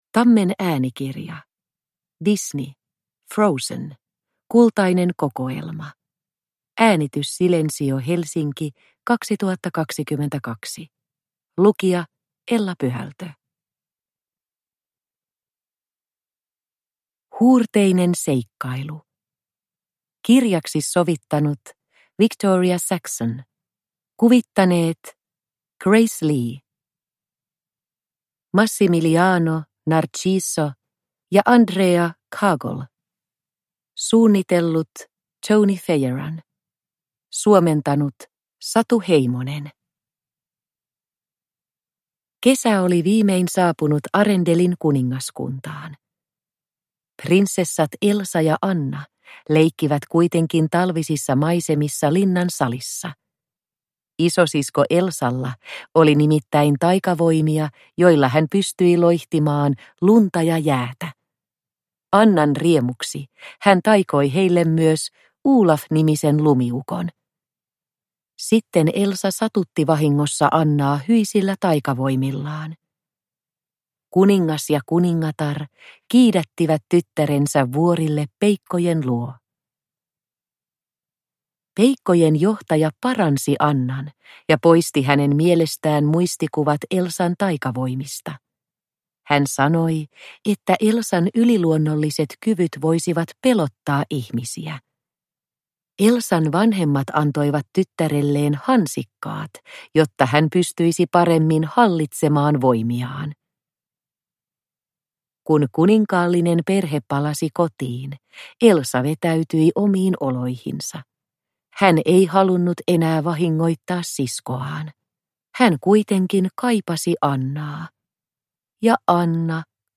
Frozen. Kultainen kokoelma – Ljudbok – Laddas ner